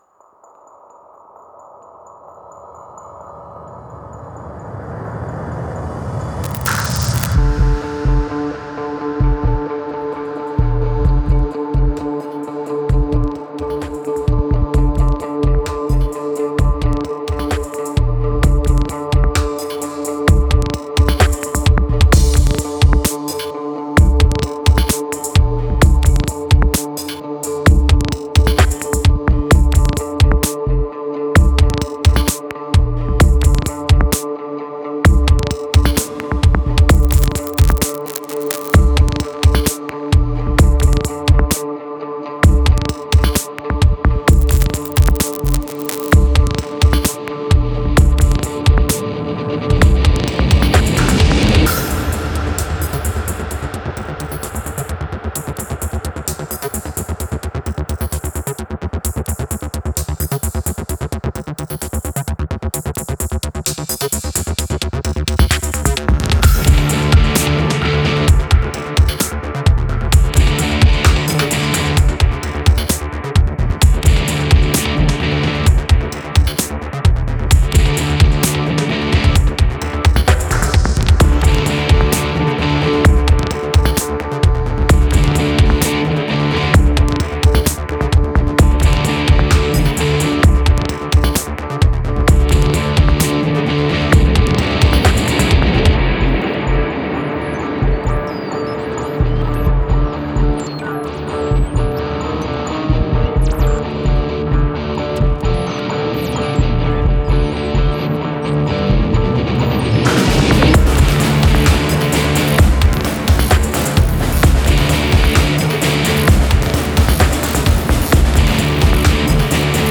These are dark and mysterious conspiracy sounds.